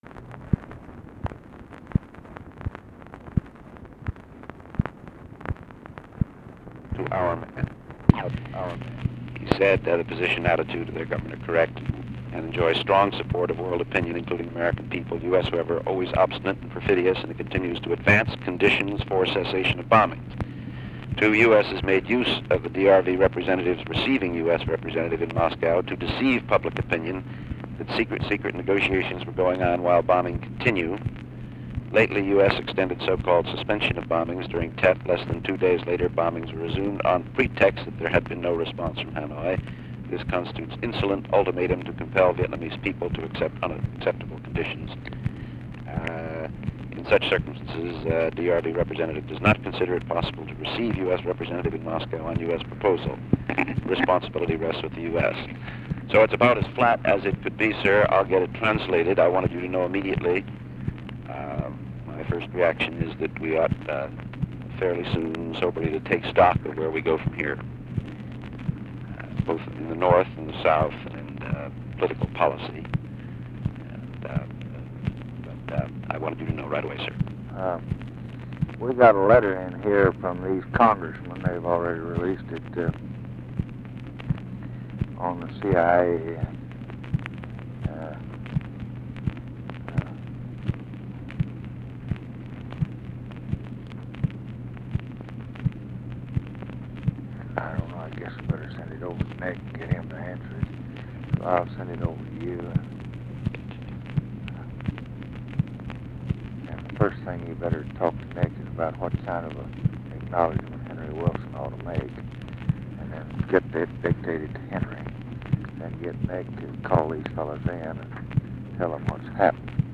Conversation with WALT ROSTOW, February 15, 1967
Secret White House Tapes